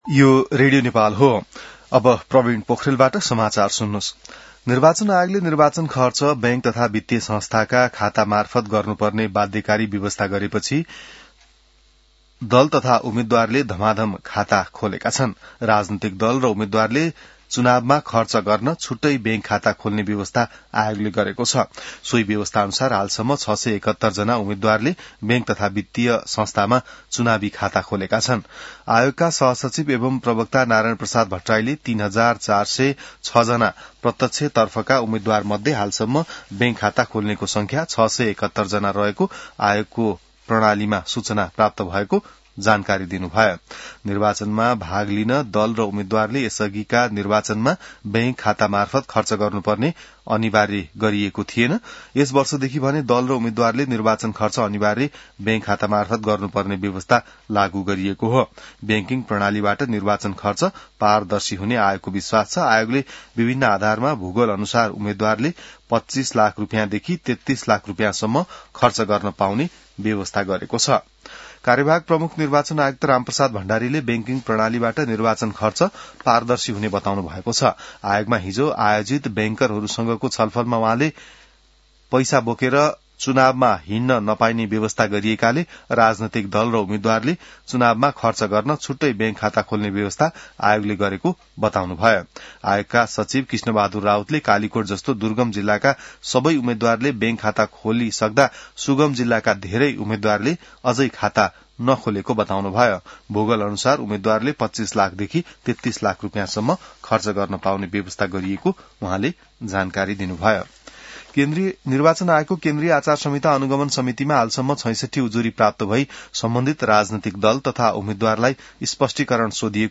An online outlet of Nepal's national radio broadcaster
बिहान ६ बजेको नेपाली समाचार : ५ फागुन , २०८२